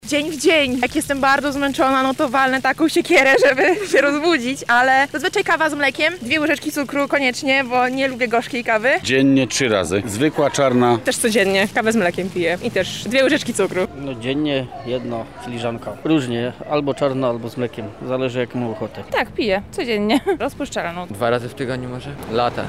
Zapytaliśmy mieszkańców o to jak często i jaką kawę piją najczęściej.